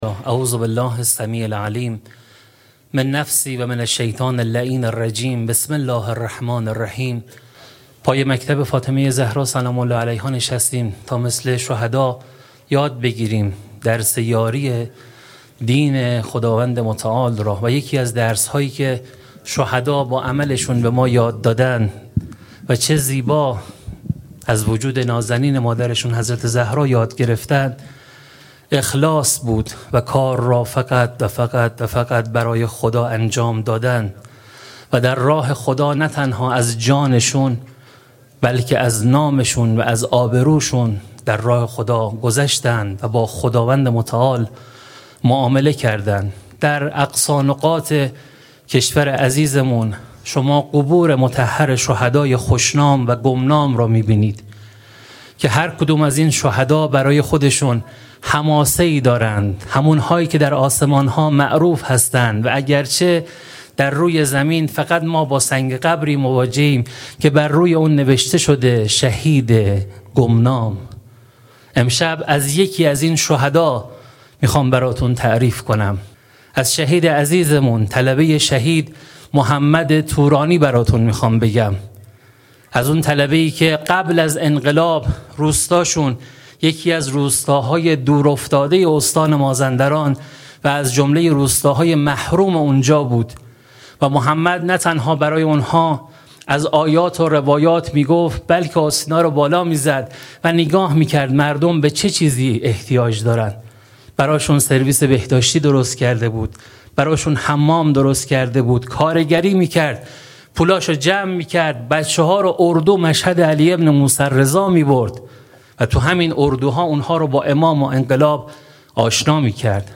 مراسم سوگواری شهادت حضرت فاطمه زهرا سلام‌الله‌علیها در جامعه الزهرا سلام‌الله‌علیها
مراسم سوگواری شهادت حضرت فاطمه زهرا سلام‌الله‌علیها با عنوان «حدیث اشک» به مدت سه شب با حضور همه اقشار جامعه در جامعه الزهرا سلام‌الله‌علیها برگزار شد.